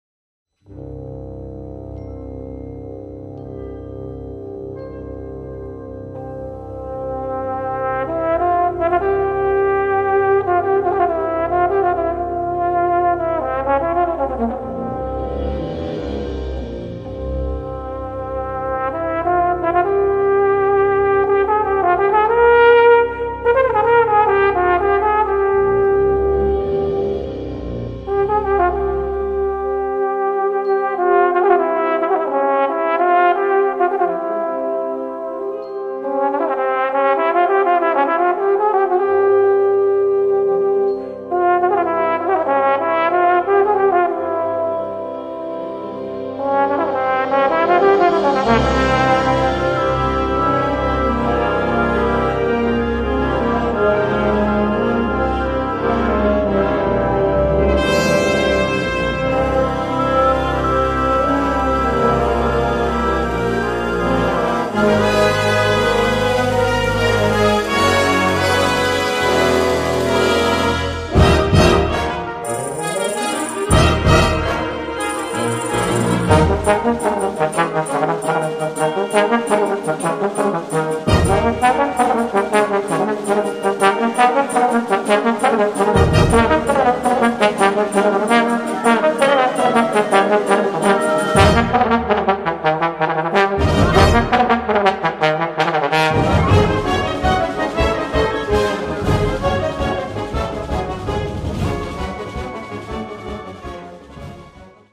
Voicing: Trombone and Brass Band